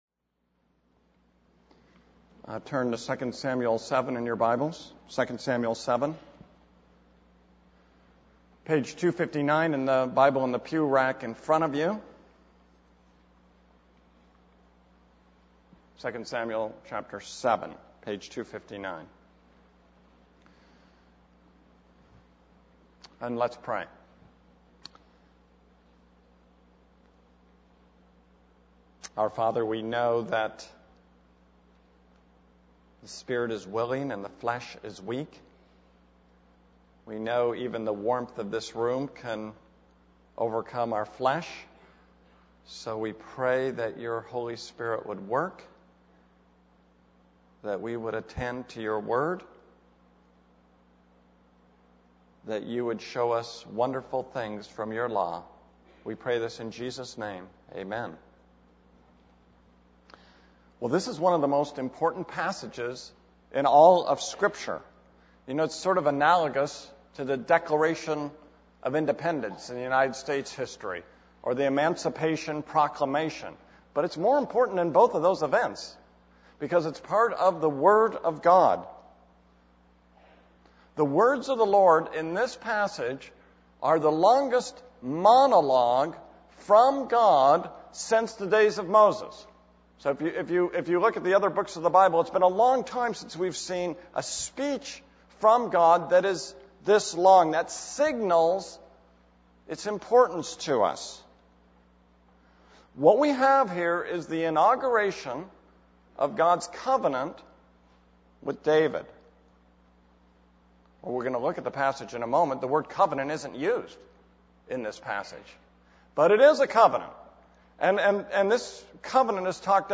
This is a sermon on 2 Samuel 7:1-29.